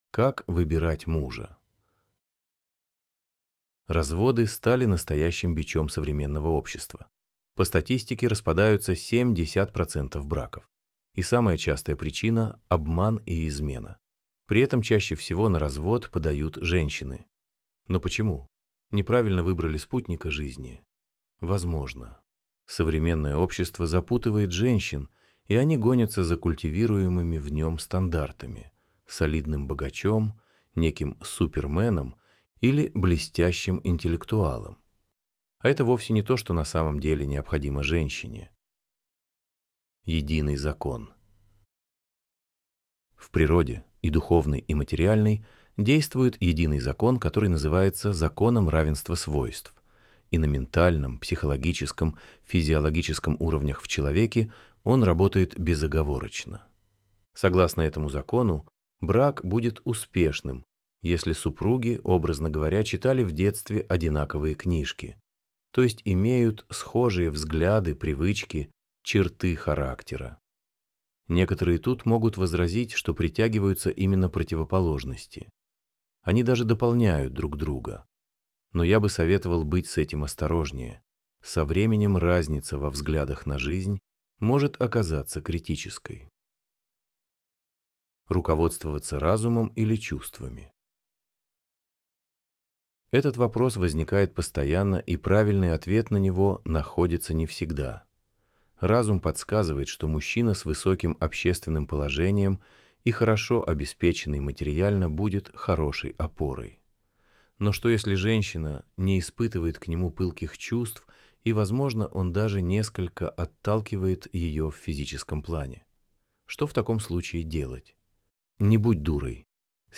Аудиоверсия статьи